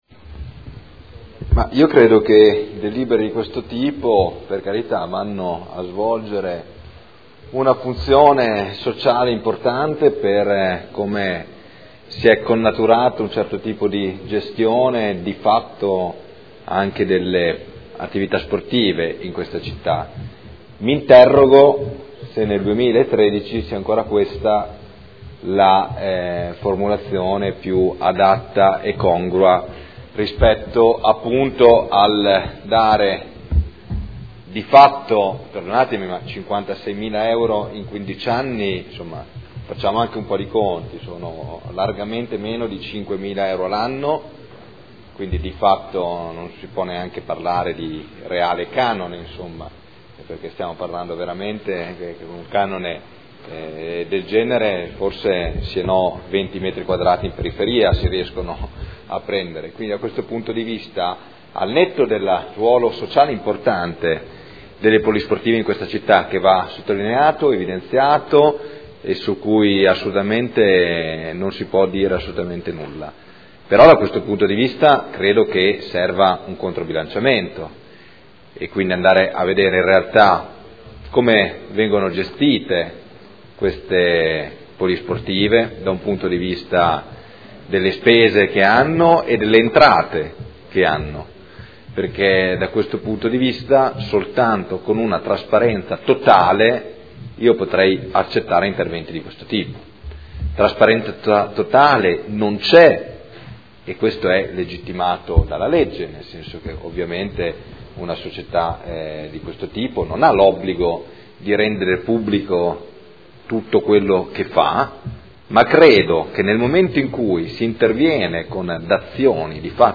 Seduta del 19 dicembre. Proposta di deliberazione: Regolamento per l’assegnazione in diritto di superficie – Proroga del diritto di superficie alla Polivalente Cognentese Società Cooperativa a r.l. Dichiarazioni di voto